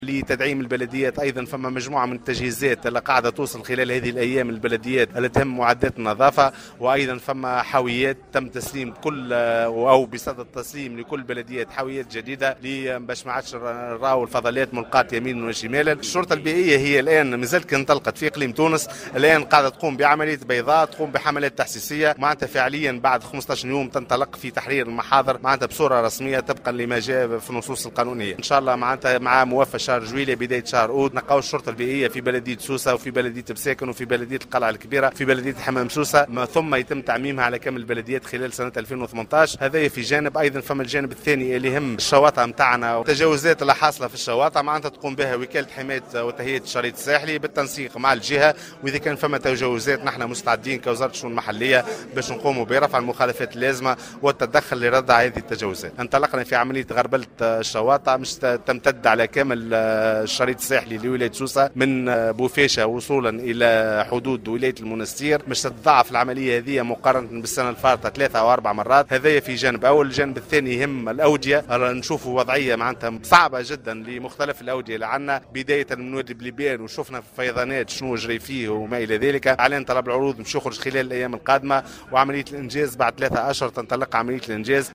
وأضاف بن حسن في تصريح لـ"الجوهرة أف أم" على هامش زيارة أداها اليوم الثلاثاء إلى ولاية سوسة أن أعوان الشرطة البيئية سينطلقون في العمل موفى شهر جويلية الحالي وبداية شهر أوت المقبل وذلك في بلديات سوسة و القلعة الكبرى وحمام سوسة و مساكن، ليتم تعميمها على كافة البلدية خلال السنة المقبلة.